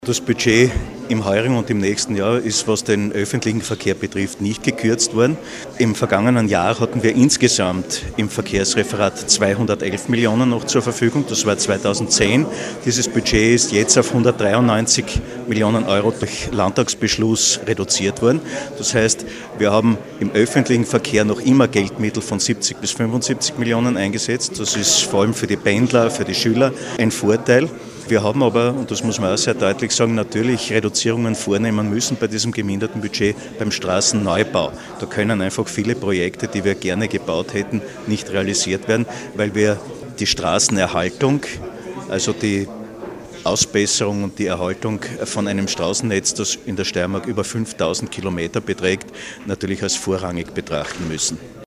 Landesrat Gerhard Kurzmann: